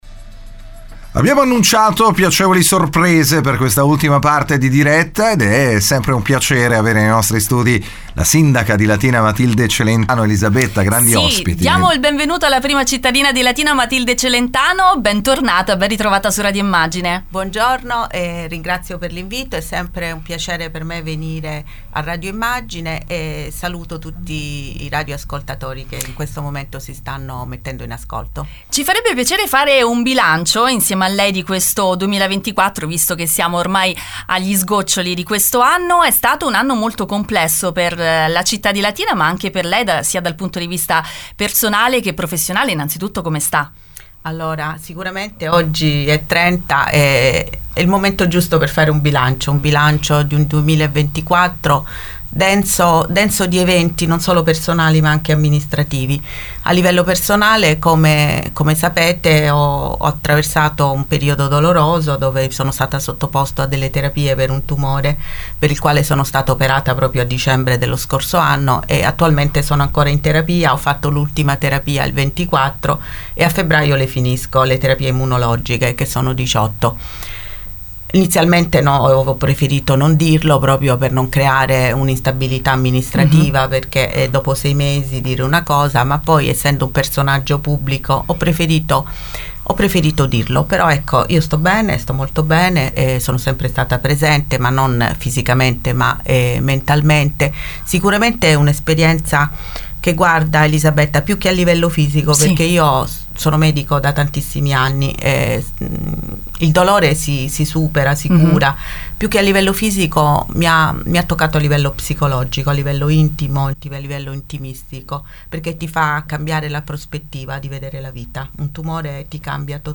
L’intervista in diretta su Radio Immagine